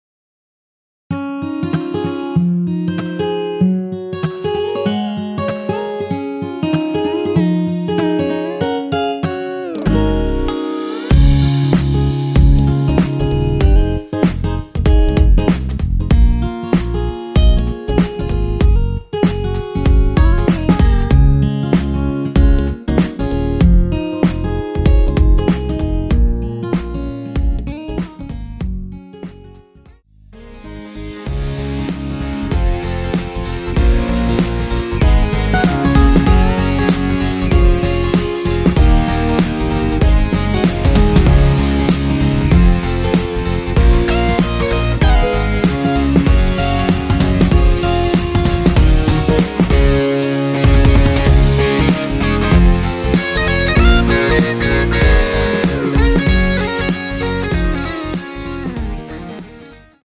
원키에서(+5)올린 MR입니다.
C#
앞부분30초, 뒷부분30초씩 편집해서 올려 드리고 있습니다.
중간에 음이 끈어지고 다시 나오는 이유는